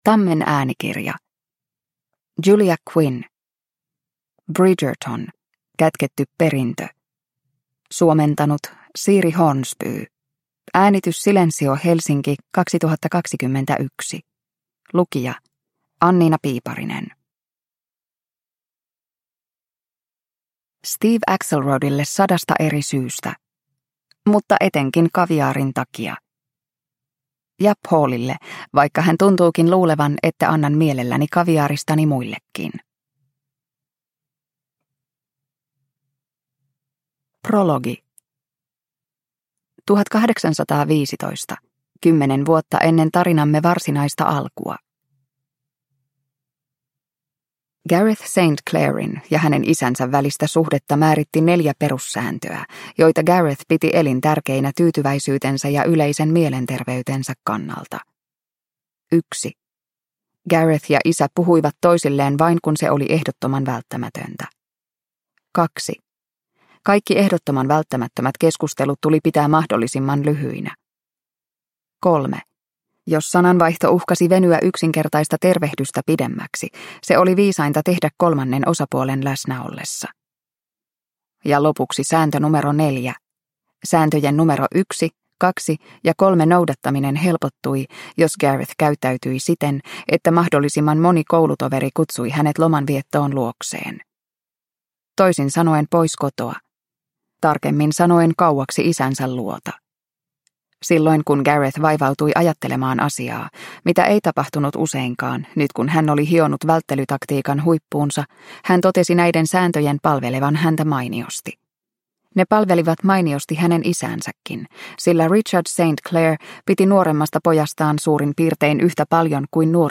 Bridgerton: Kätketty perintö – Ljudbok – Laddas ner
Produkttyp: Digitala böcker